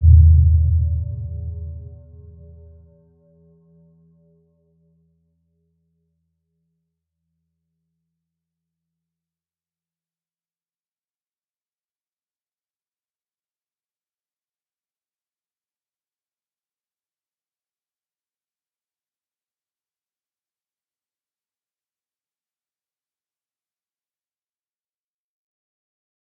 Round-Bell-E2-mf.wav